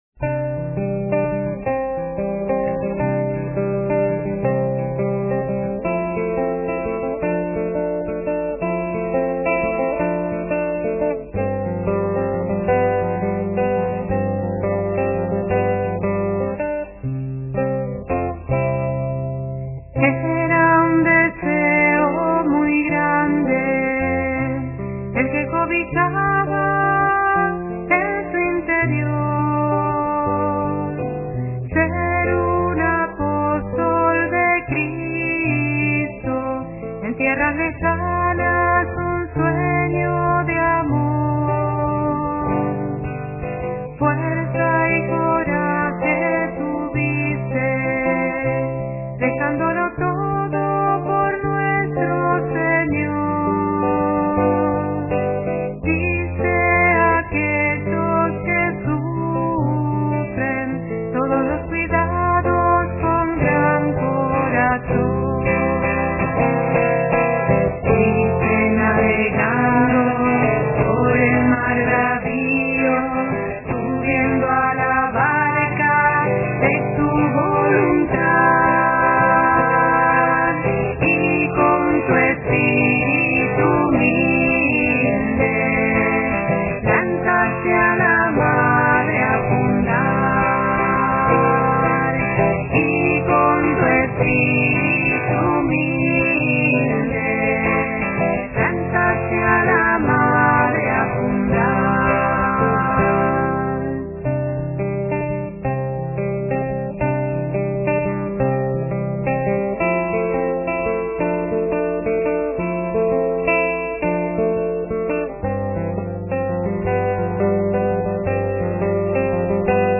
Un gran deseo (Canción) Era un deseo muy grande el que cobijaba en su interior, ser un apóstol de Cristo en tierras lejanas un sueño de amor.